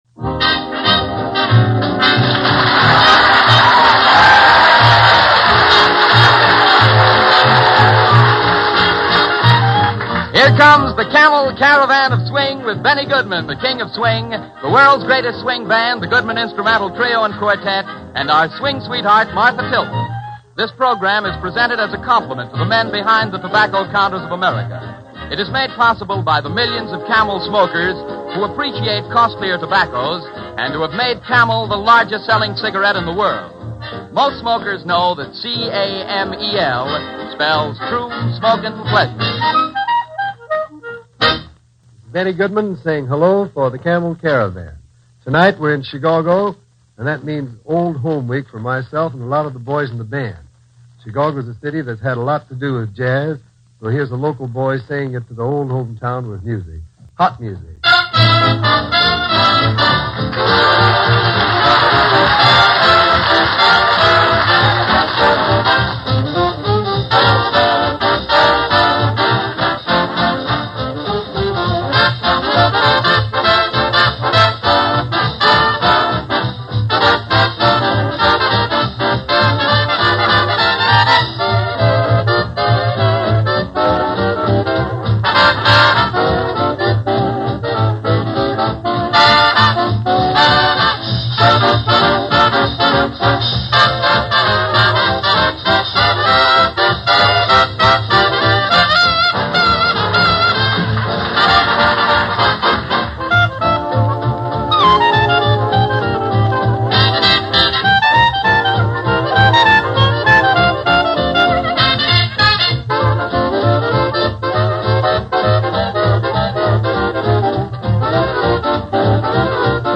Big Bands
live material
I ran across this excellent sounding example
Swing Music